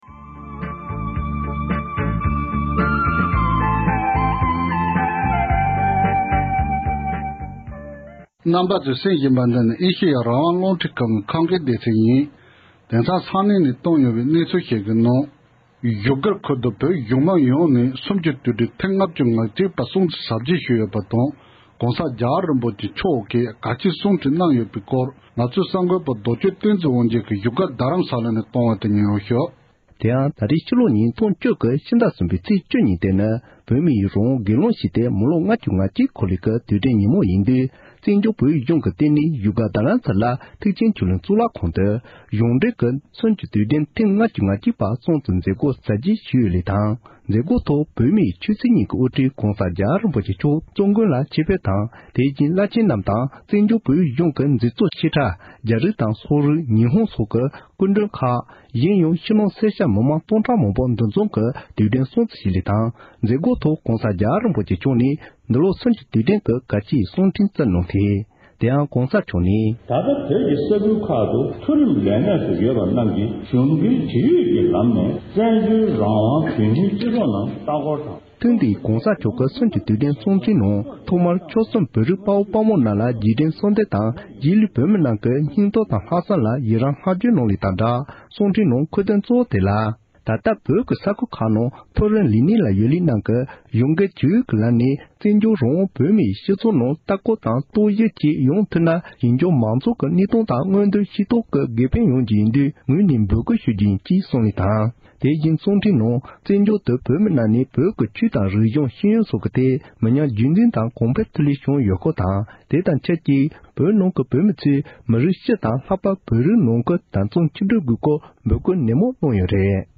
བཙན་བྱོལ་བོད་གཞུང་མང་ནས་སུམ་བཅུ་དུས་དྲན་སྲུང་བརྩི། ༸གོང་ས་མཆོག་གིས་སུམ་བཅུ་དུས་དྲན་ཐེངས་ལྔ་བཅུ་ང་གཅིག་པའི་སྲུང་བརྩི་མཛད་སྒོའི་ཐོག་གལ་ཆེའི་བཀའ་སློབ་སྩོལ་བཞིན་པ།